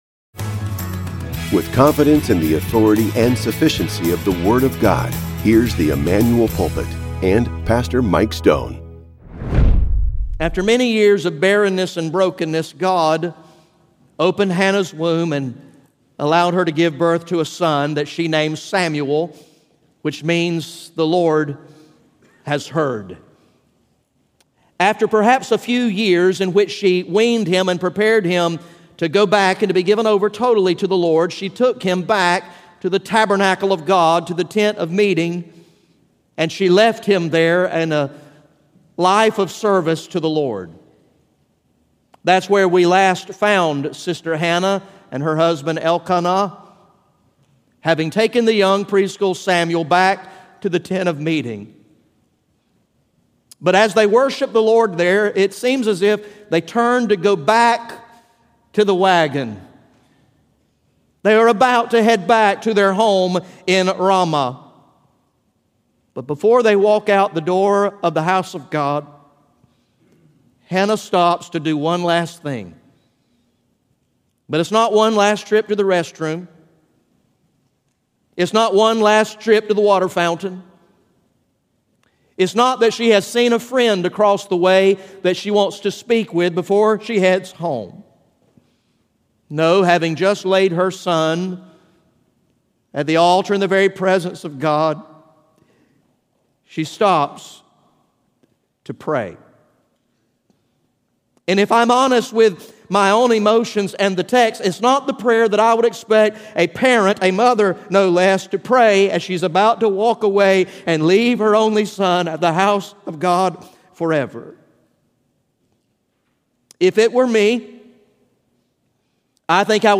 GA Message #04 from the sermon series entitled “Long Live the King!